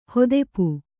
♪ hodepu